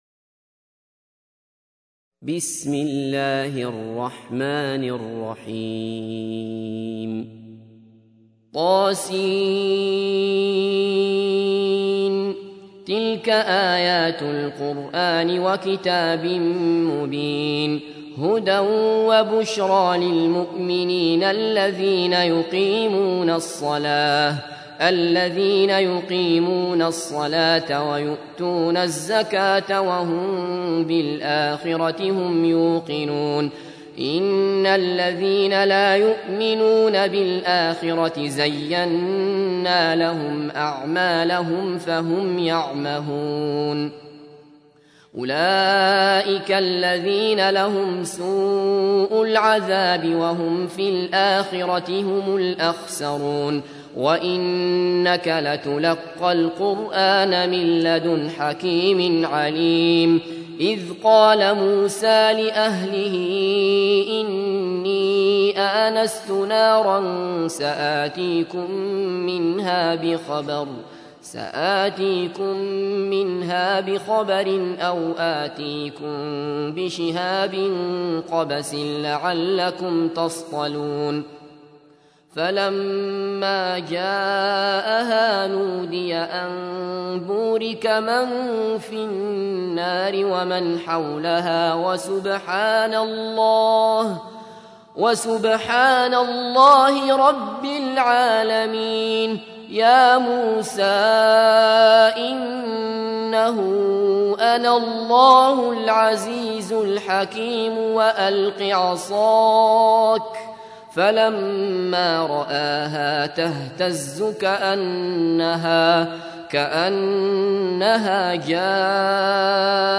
تحميل : 27. سورة النمل / القارئ عبد الله بصفر / القرآن الكريم / موقع يا حسين